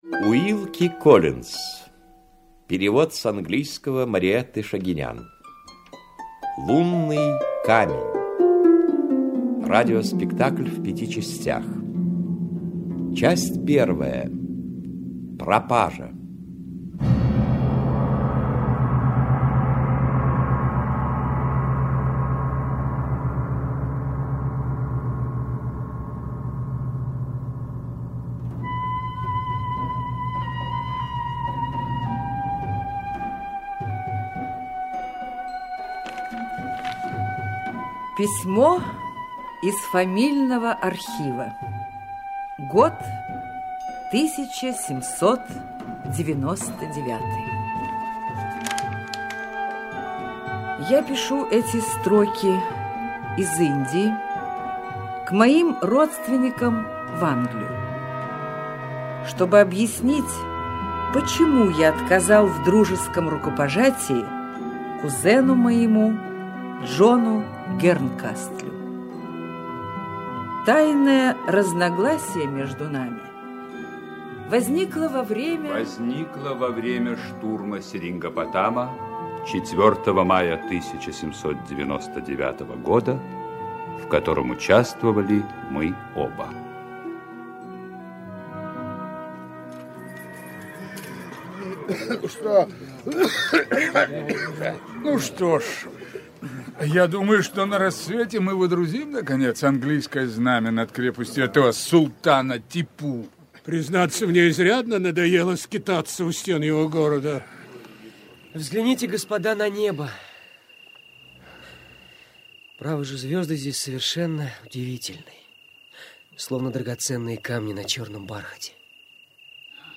Аудиокнига Лунный камень. Аудиоспектакль | Библиотека аудиокниг
Аудиоспектакль Автор Уилки Коллинз Читает аудиокнигу Сергей Юрский.